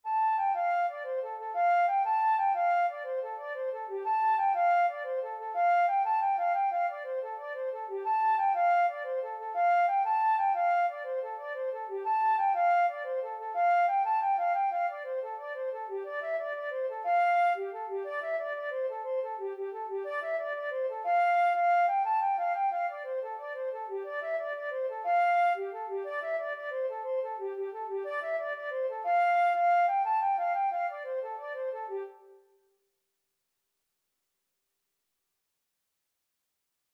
Traditional Trad. Child of My Heart (Irish Folk Song) Flute version
6/8 (View more 6/8 Music)
G5-A6
F major (Sounding Pitch) (View more F major Music for Flute )
Traditional (View more Traditional Flute Music)